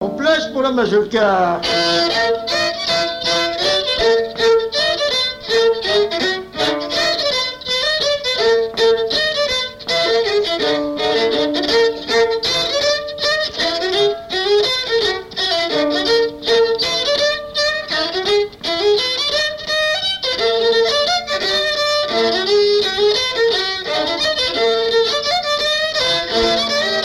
Mémoires et Patrimoines vivants - RaddO est une base de données d'archives iconographiques et sonores.
Mazurka
danse : mazurka
Pièce musicale inédite